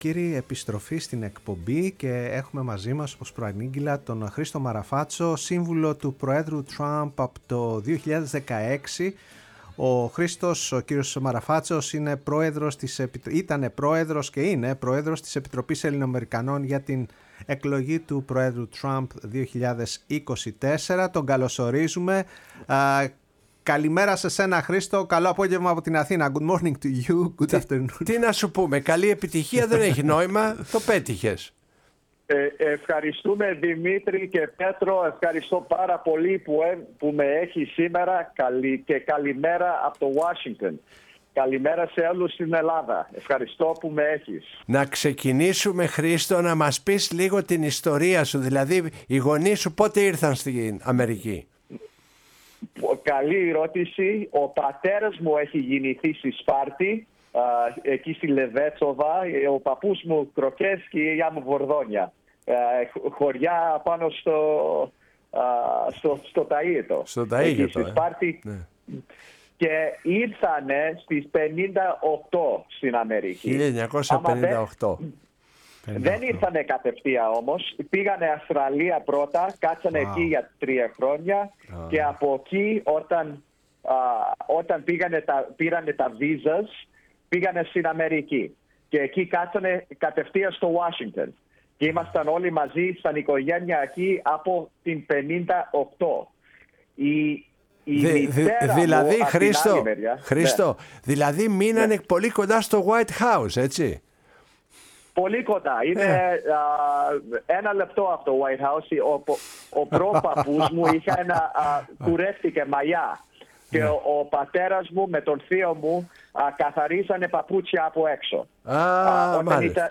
στο ραδιόφωνο της Φωνής της Ελλάδας και στην εκπομπή “Η Παγκόσμια Φωνή μας”